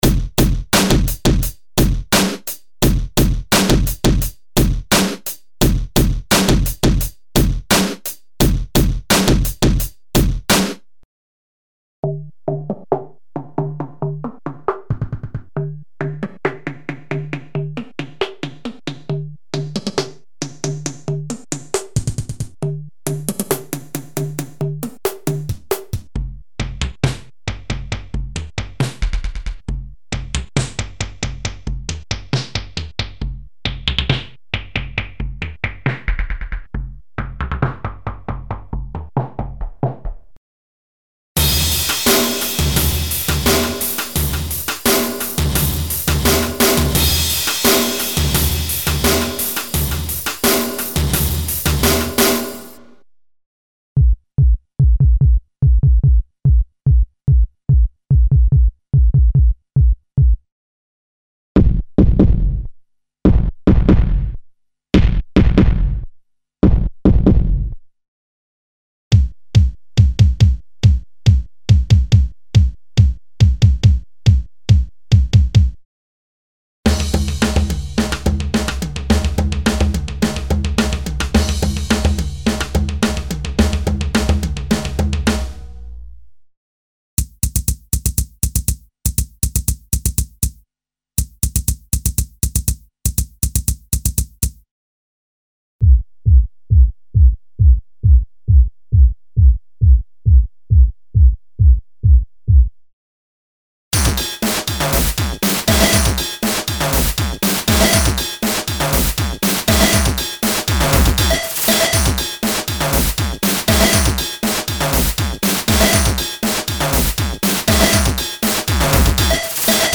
Acoustic, electronic and experimental drums & percussion - drum kits and single drum programs for a wide variety of music styles, ranging from Jazz, Rock and Ethno to Hip-Hop and underground electronic music. These programs were created to take advantage of the special controller settings for internal DSP modulations (e.g. filter, pitch, shaper, distortion, etc.).
Info: All original K:Works sound programs use internal Kurzweil K2500 ROM samples exclusively, there are no external samples used.
K-Works - Drums & Percussion - LE - Special Bundle (Kurzweil K2xxx).mp3